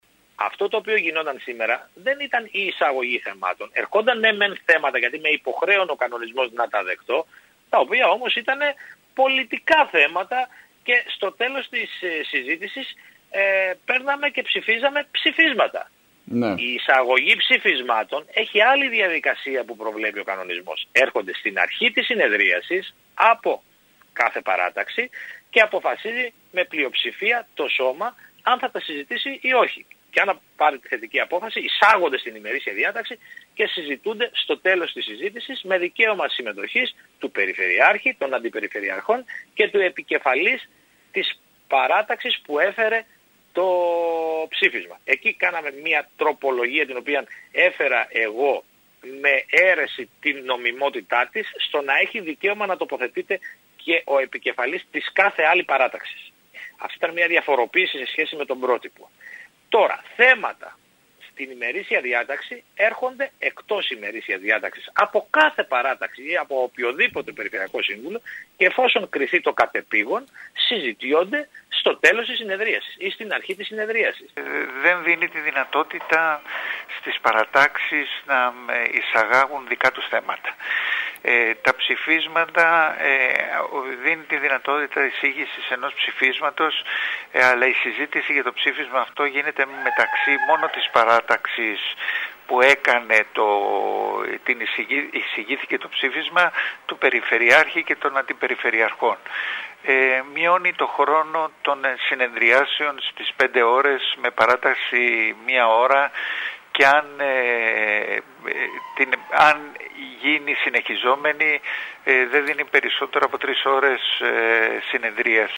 Ακούμε και πάλι τους κ.κ. Μουζακίτη και Γαβαλά.